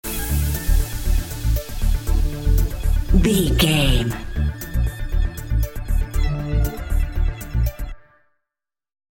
Epic / Action
Fast paced
In-crescendo
Aeolian/Minor
groovy
uplifting
energetic
bouncy
synthesiser
drum machine
house
electronic
instrumentals
synth bass
upbeat